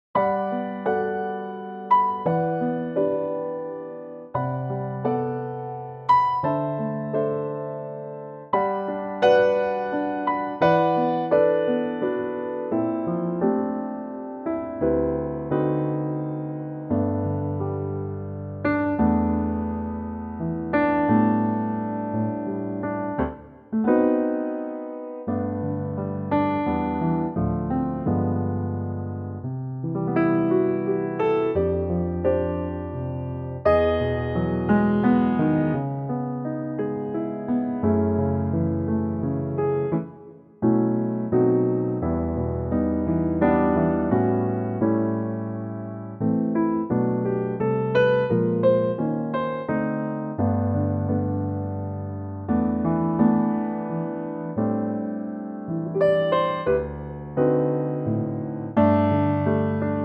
key - G - vocal range - E to B (huge range)